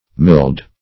Milled \Milled\ (m[i^]ld), a.